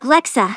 alexa/ovos-tts-plugin-deepponies_Starlight_en.wav · OpenVoiceOS/synthetic-wakewords at main
synthetic-wakewords
ovos-tts-plugin-deepponies_Starlight_en.wav